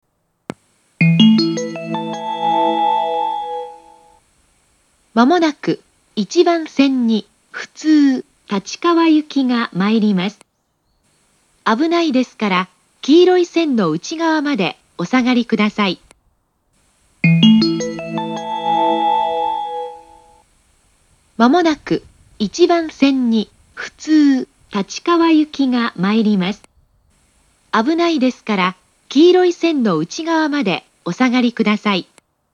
旧スピーカー（現在は撤去されております）
１番線接近放送
旧型のＡＴＯＳ放送でした。
hatukari1bansen-sekkin.mp3